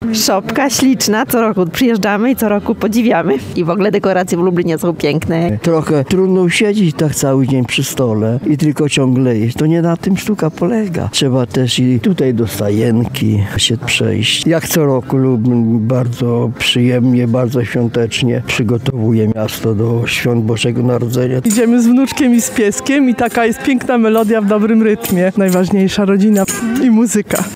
Dekoracje w Lublinie są piękne – mówią spacerowicze.